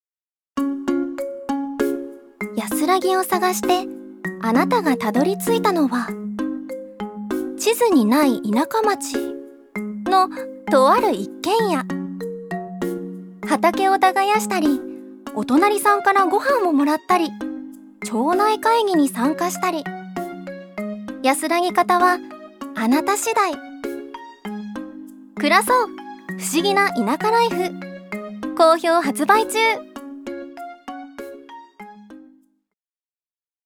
ジュニア：女性
ナレーション１